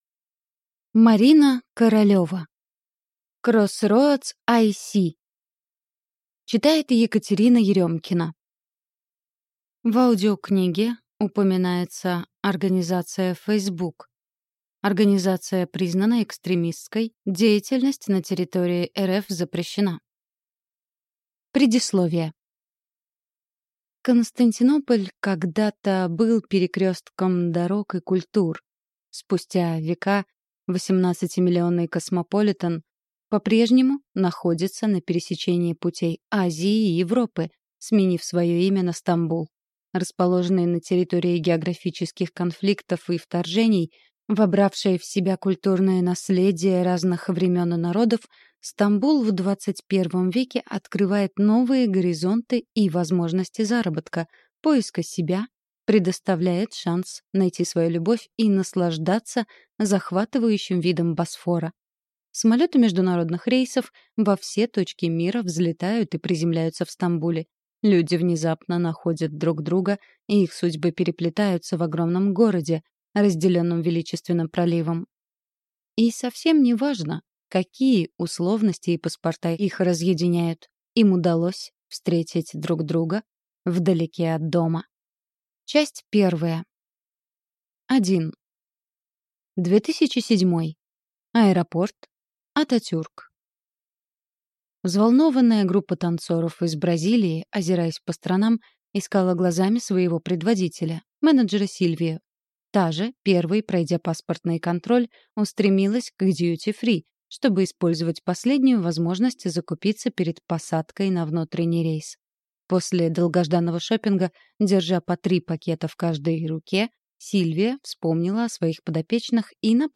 Аудиокнига CrossRoads IC | Библиотека аудиокниг